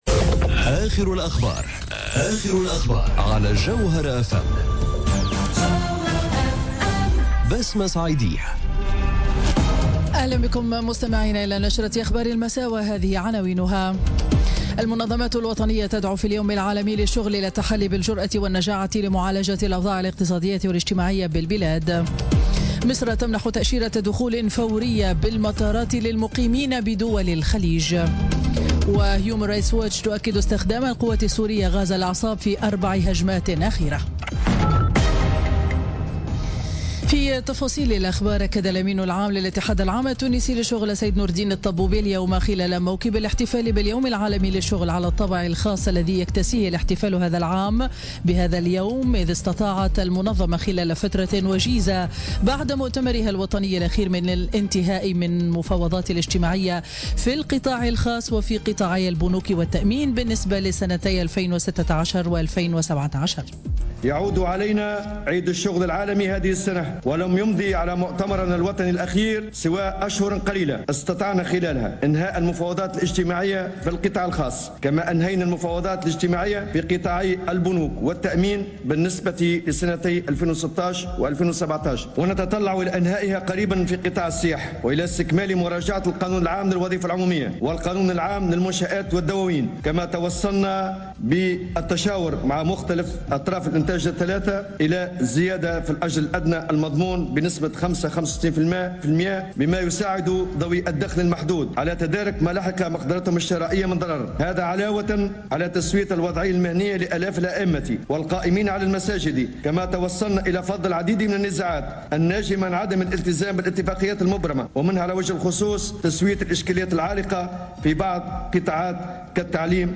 نشرة أخبار السابعة مساء ليوم الاثنين غرة ماي 2017